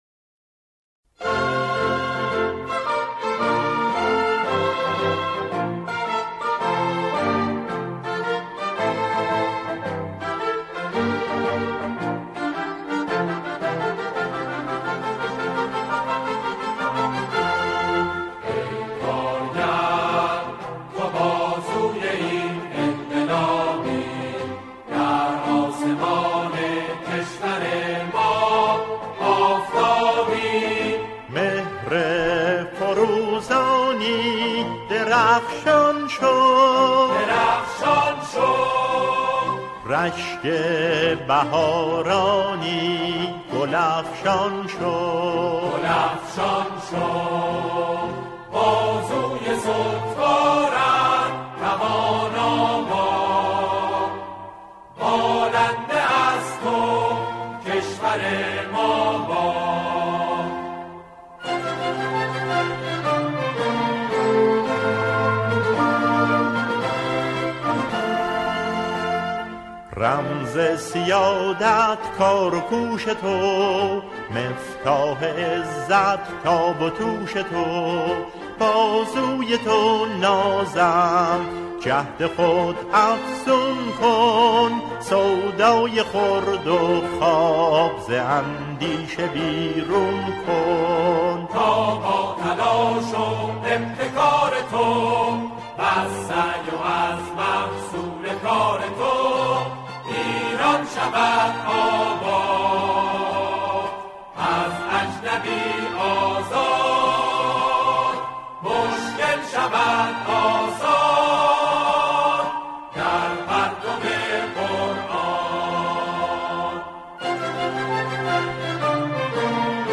سرودهای روز کارگر